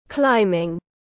Môn leo núi tiếng anh gọi là climbing, phiên âm tiếng anh đọc là /ˈklaɪ.mɪŋ/ .
Climbing /ˈklaɪ.mɪŋ/
Climbing.mp3